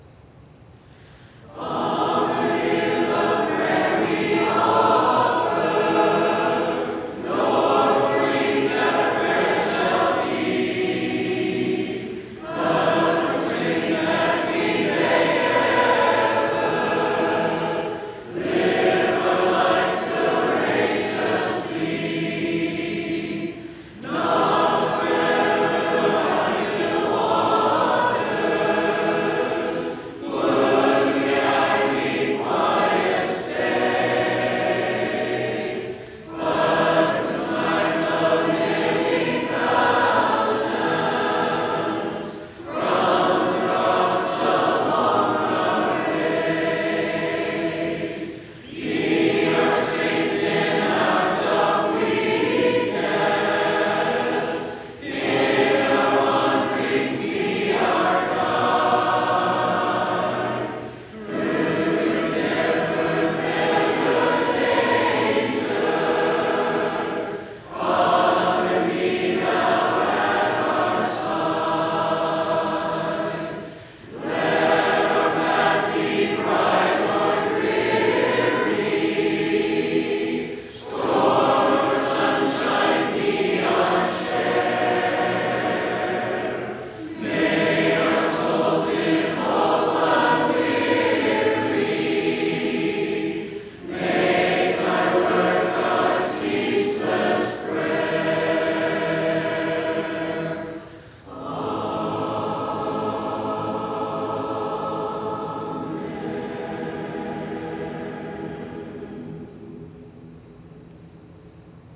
Public Domain Sheet Music and Acapella MP3 Files